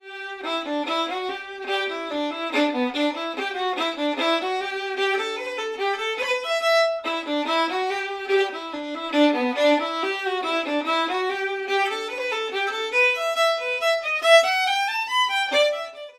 Researching the historical fiddle music of Dumfries & Galloway